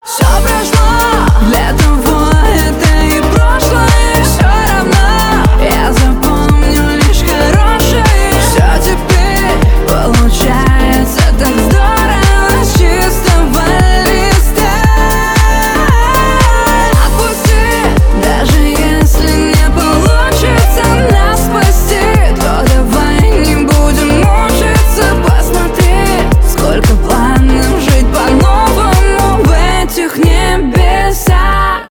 счастливые , поп
танцевальные